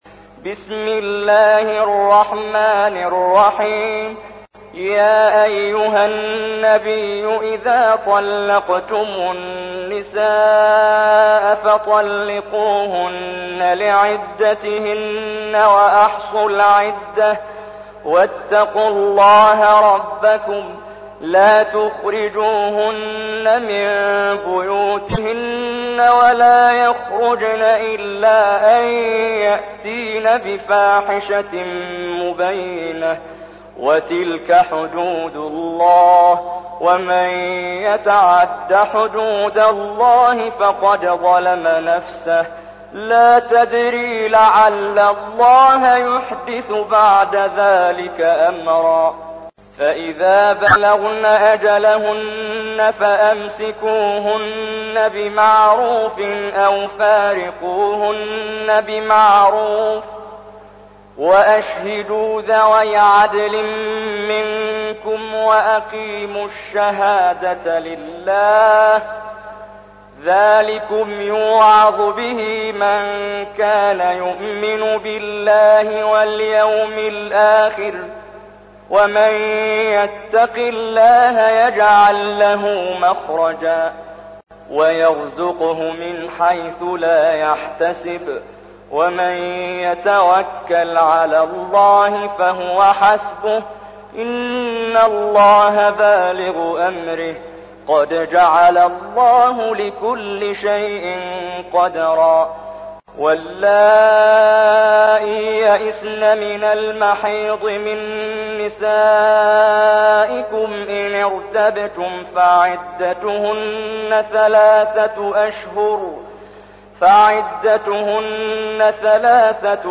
تحميل : 65. سورة الطلاق / القارئ محمد جبريل / القرآن الكريم / موقع يا حسين